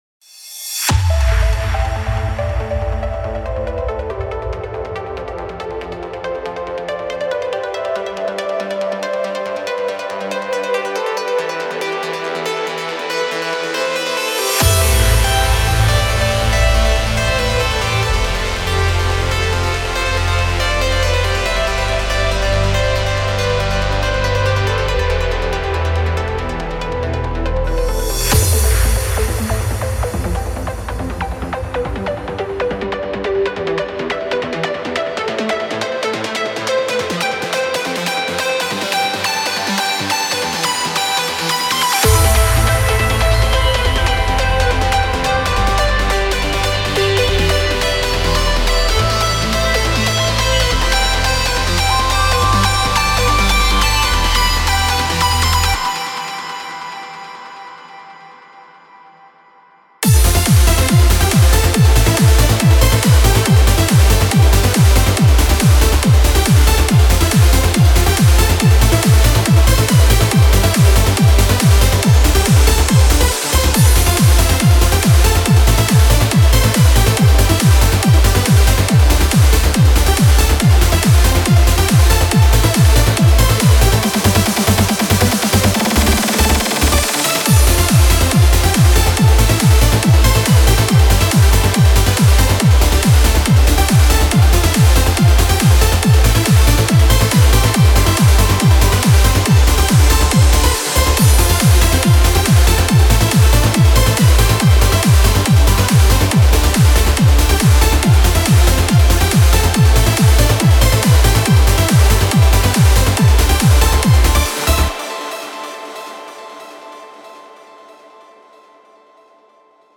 Trance Uplifting Trance